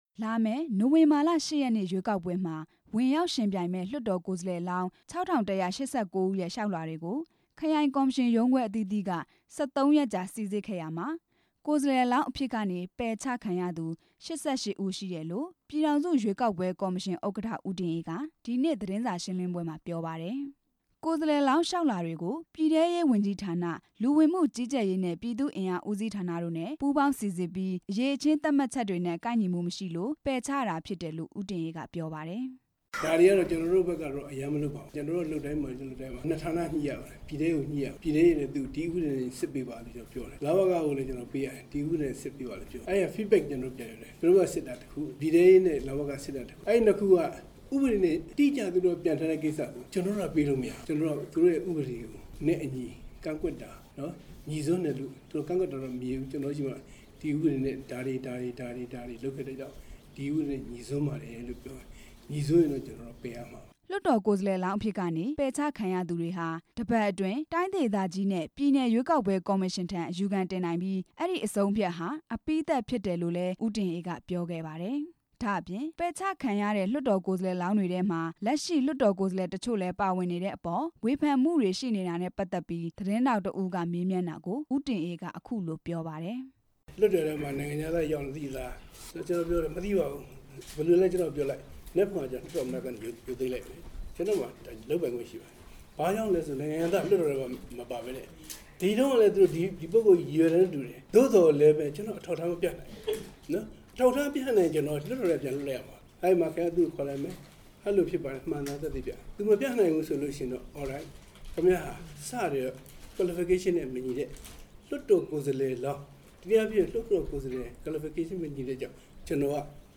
ရွေးကောက်ပွဲလုပ်ငန်းစဉ် သတင်းစာရှင်းလင်းပွဲ